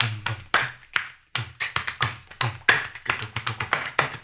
HAMBONE 12-L.wav